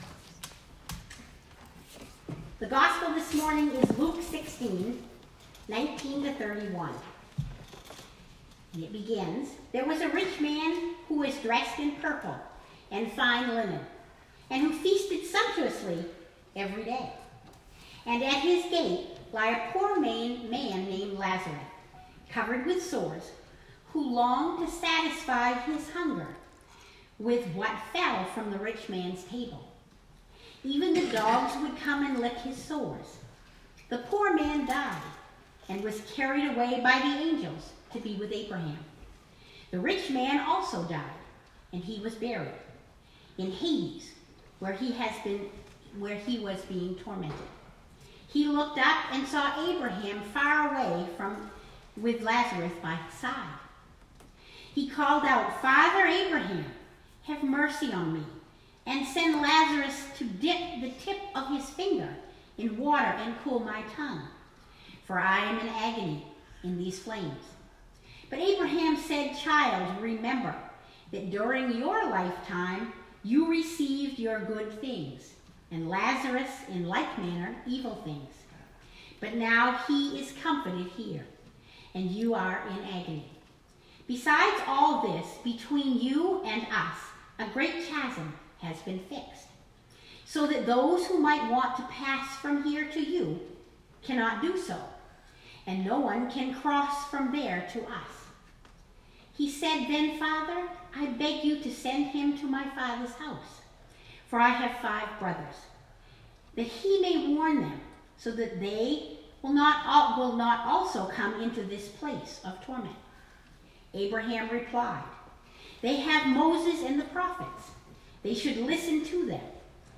Sermon 2019-09-29